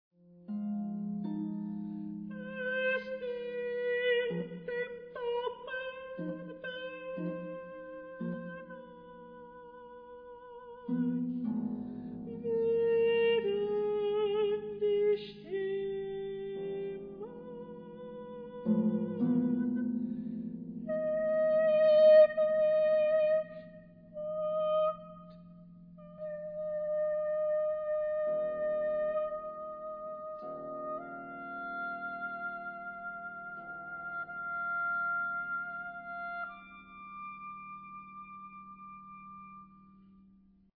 harp
counter-tenor